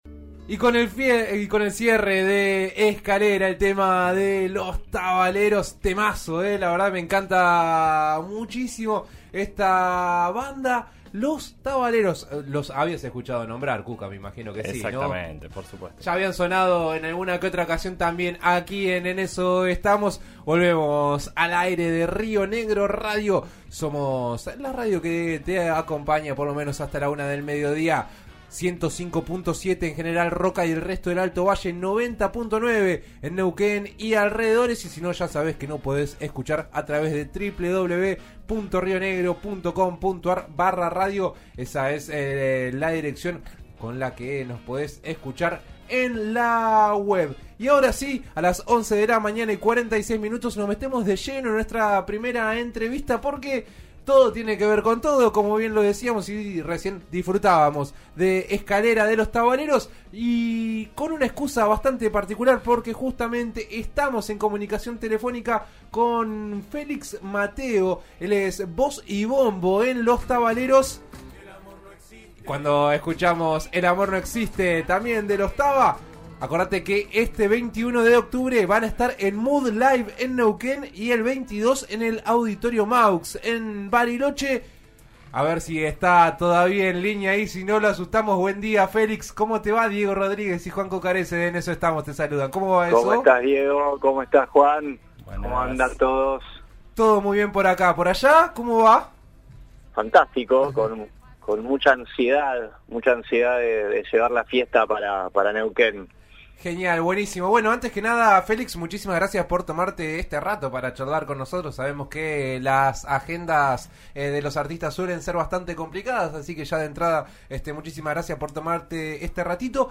Antes de los shows, pasó por los estudios de En Eso Estamos, por RN RADIO, y charló sobre todo lo que viene y cómo llegó a este presente.